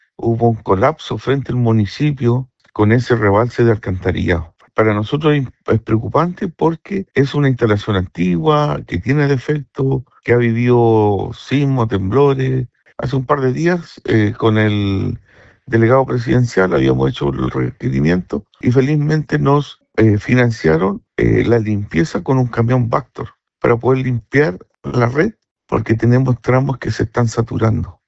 Según explicó el alcalde de Curarrehue, Daniel Parra, la resolución fue emitida el 23 de febrero por la Seremi de Salud de La Araucanía, proceso necesario para poder financiar la contratación de camiones de limpieza.
cuna-alcalde-de-curarrehue.mp3